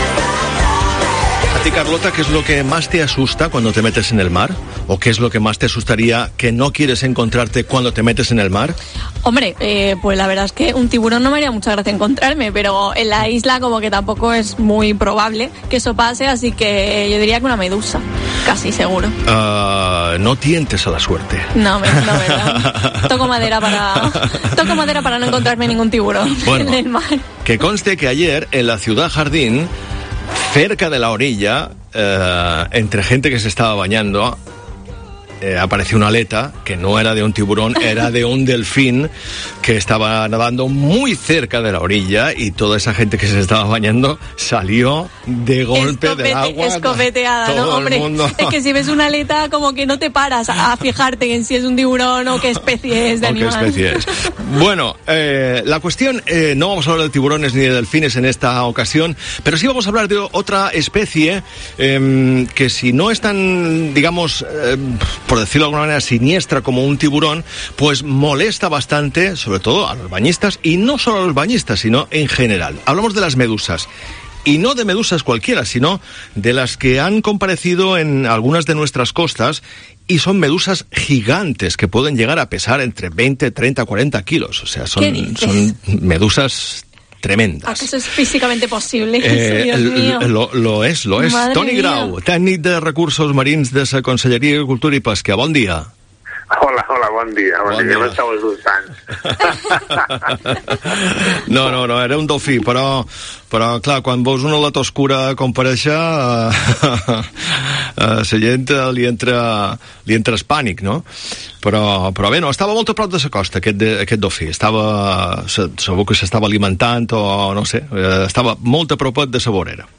Entrevista en La Mañana en COPE Más Mallorca, miércoles 25 de agosto de 2021.